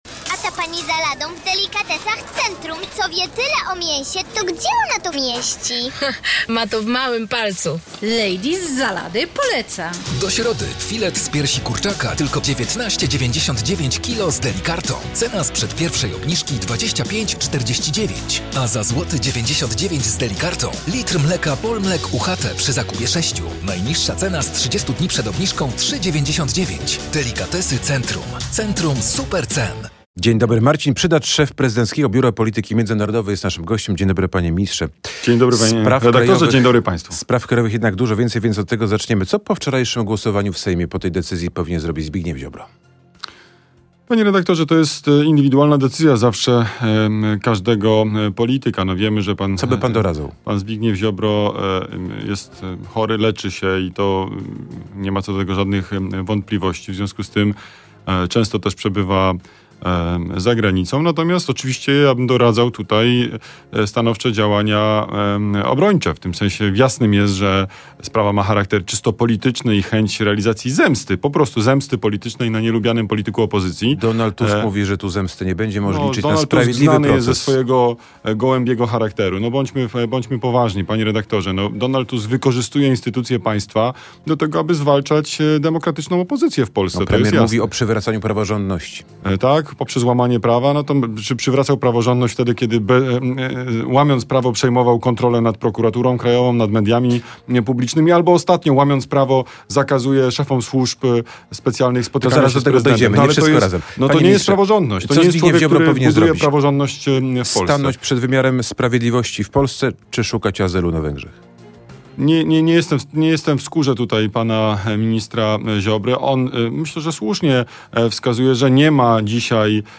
Minister w RMF FM: Myślę, że rolnicy by się na to zgodzili.
W sobotnie poranki, tuż po godz. 8:30 Krzysztof Ziemiec zaprasza na rozmowy nie tylko o polityce.